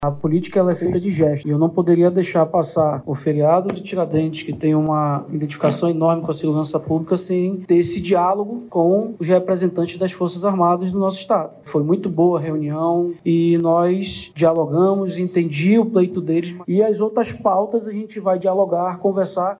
O anúncio foi feito na manhã desta quarta-feira 22/04 pelo governador interino do Estado, Roberto Cidade, que destacou a importância do diálogo com a categoria.